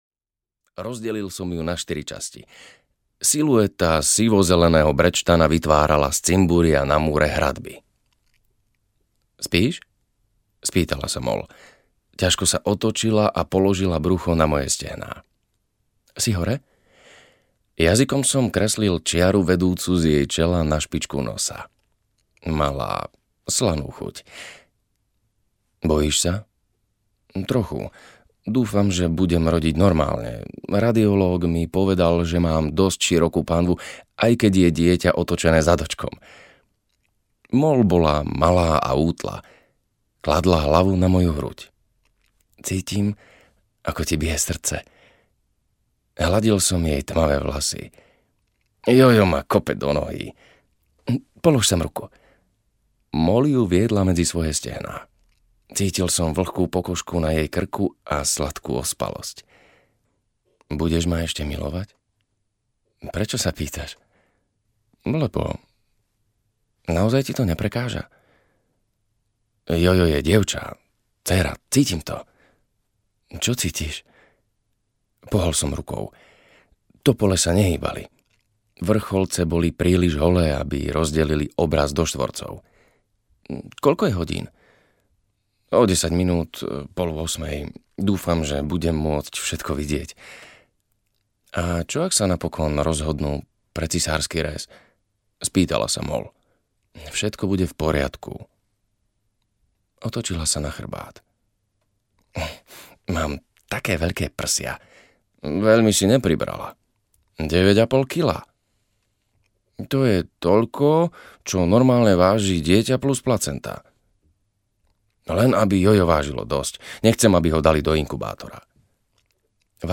Liesje audiokniha
Ukázka z knihy